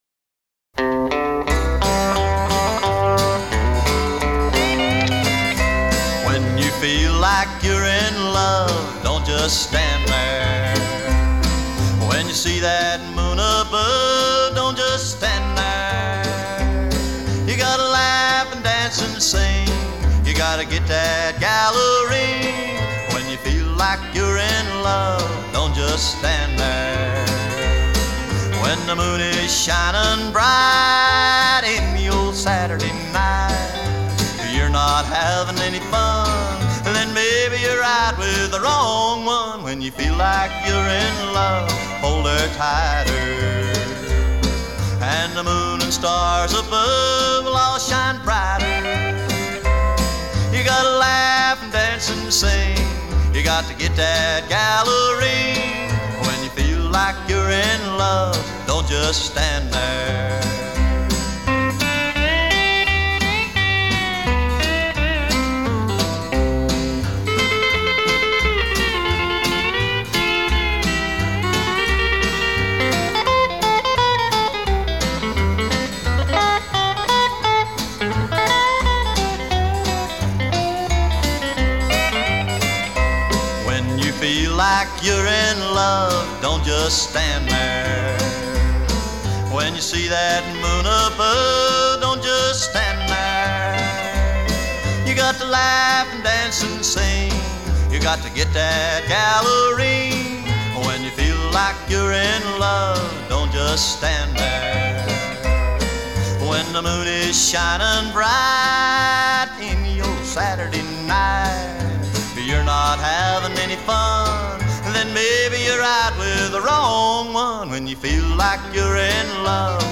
американский кантри-певец.